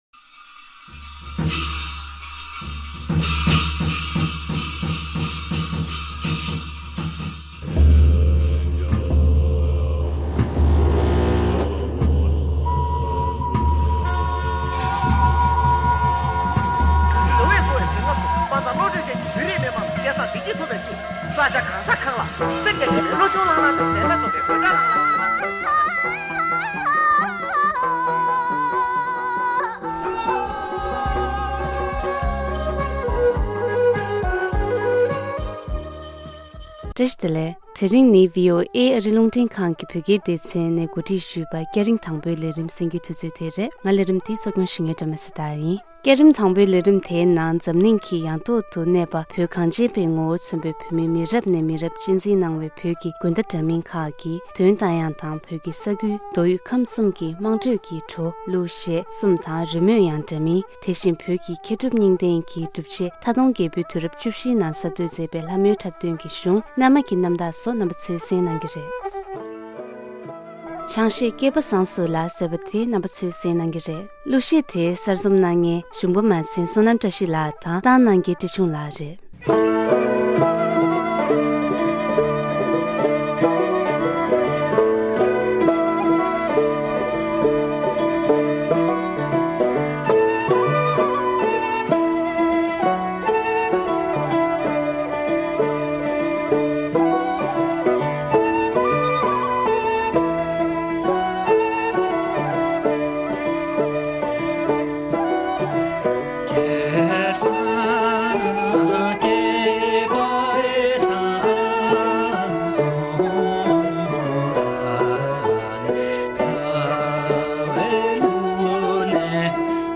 traditional Tibetan music